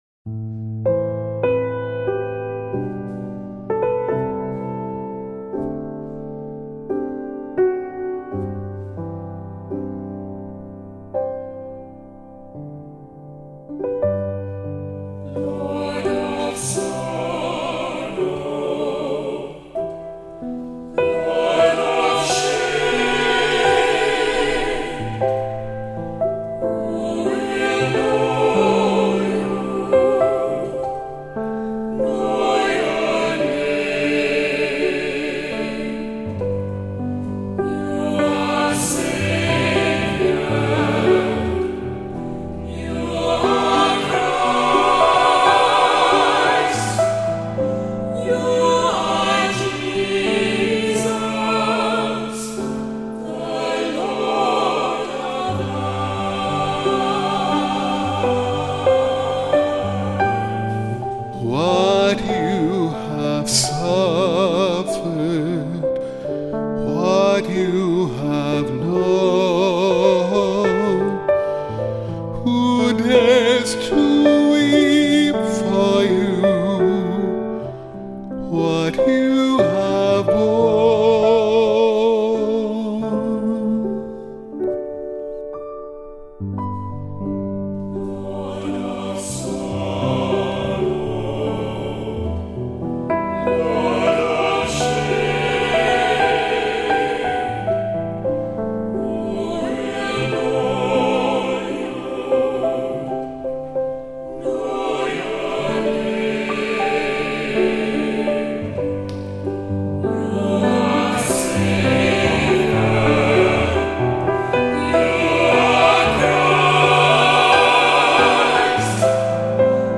Voicing: "SATB","Assembly"